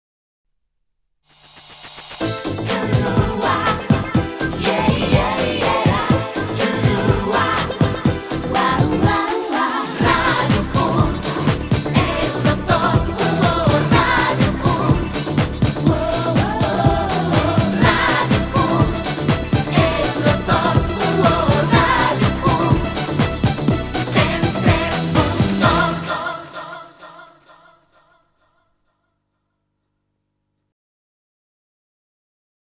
Jingle amb la identificació del programa.